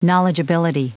Prononciation du mot knowledgeability en anglais (fichier audio)